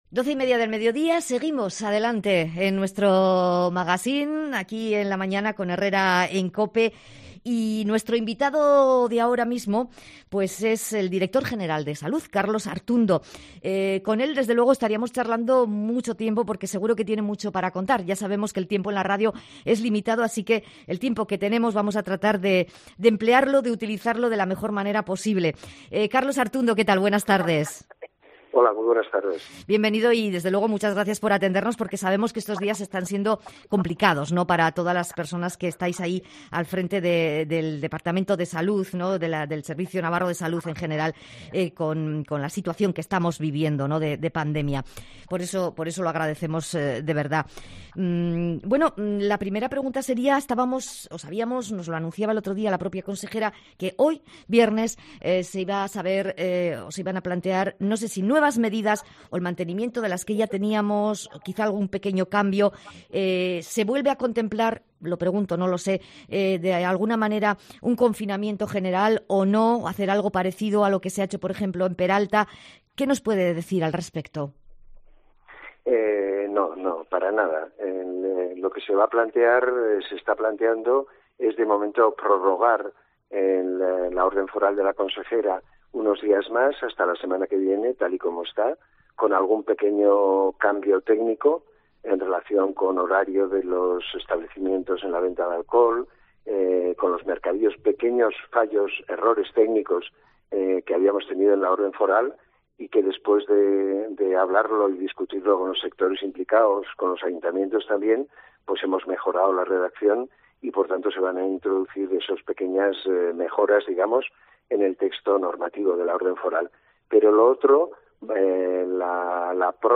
Entrevista con Carlos Artundo, director general de Salud del Gobierno de Navarra